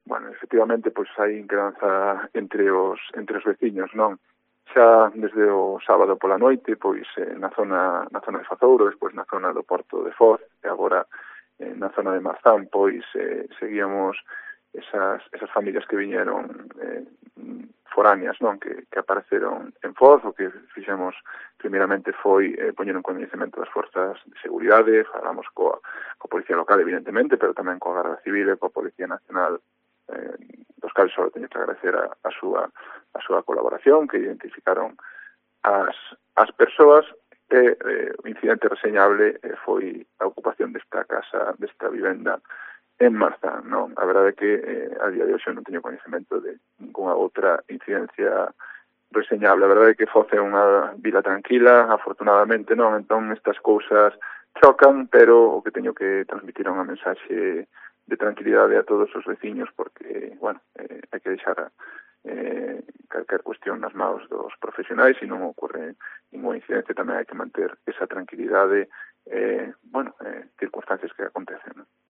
Declaraciones de FRAN CAJOTO, alcalde de Foz, sobre la 'okupación' de Marzán